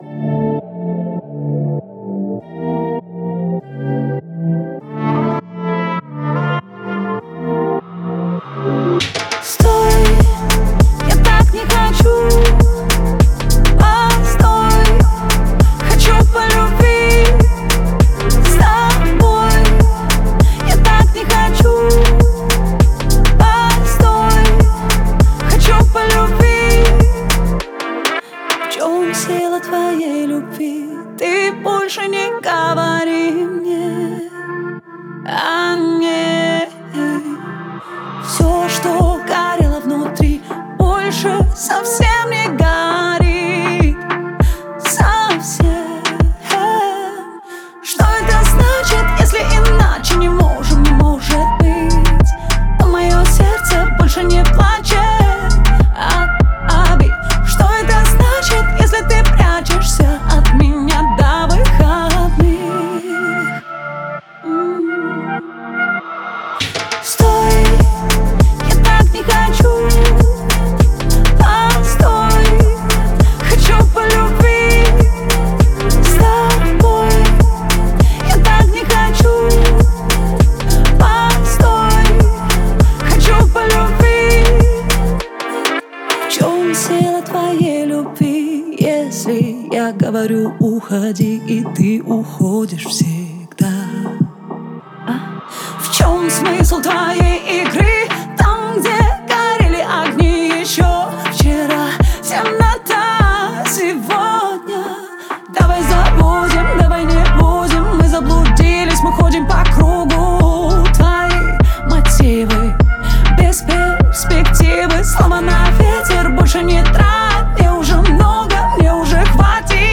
это яркая и эмоциональная песня в жанре поп